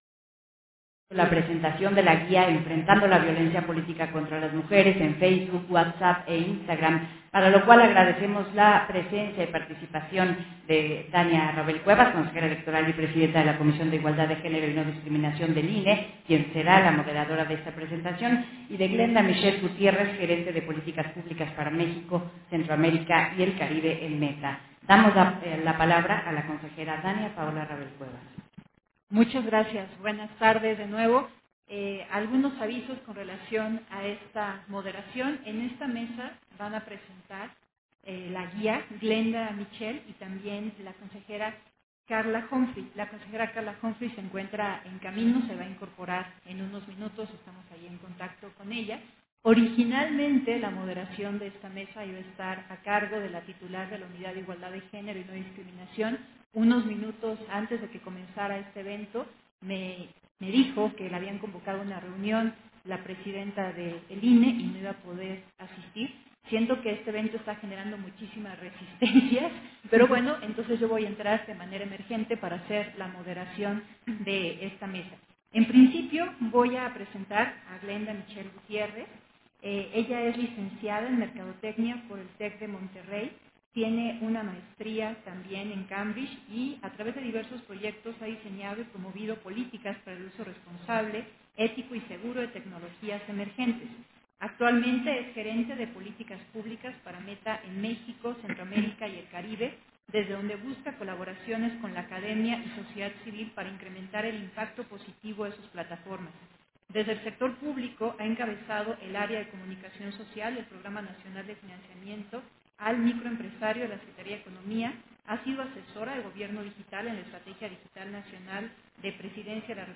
Versión estenográfica de la presentación de la guía: Enfrentando la violencia política contra las mujeres en FB, Whats app e Instagram, del foro Impacto de la violencia digital y mediática en la mujeres políticas